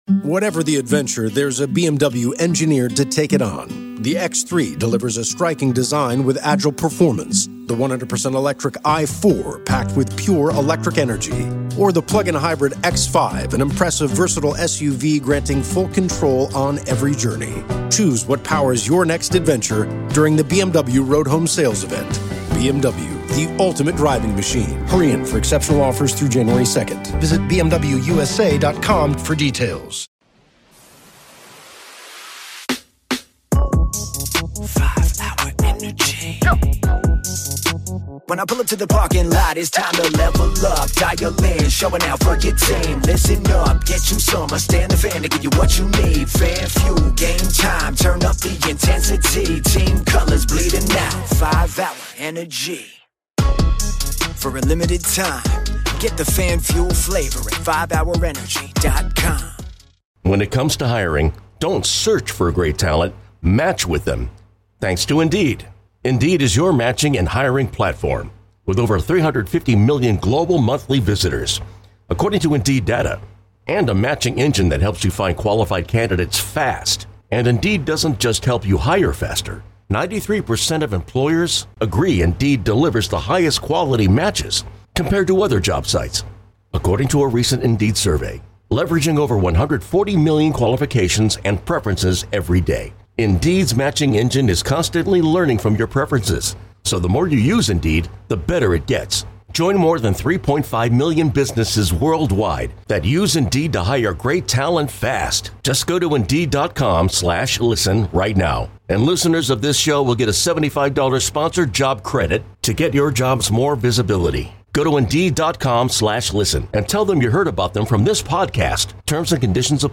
9:00 HOUR: T.J. Lang joins us live in-studio